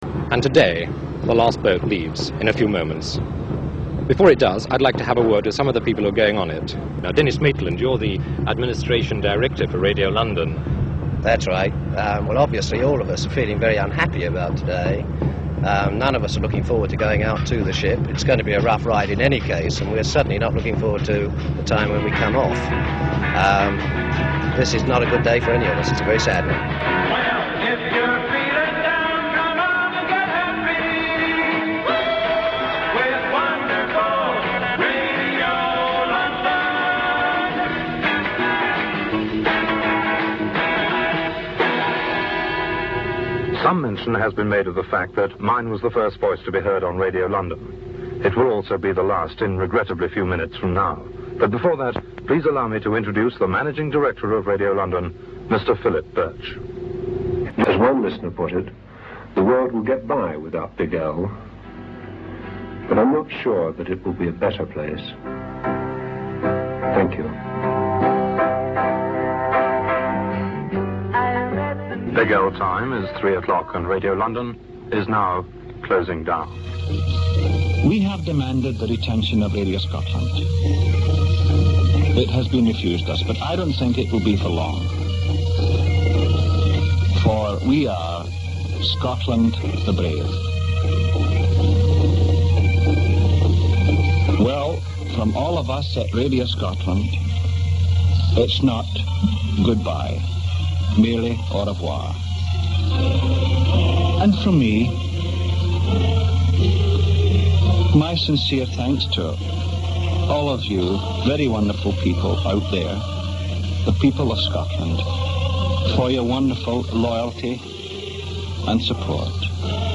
Enjoy here some news soundtrack just before Radio London ended, then its valedictory words, just before Radio Scotland and Radio 270 also say farewell. Then, Johnnie Walker issues his defiant ‘Caroline continues’ recital.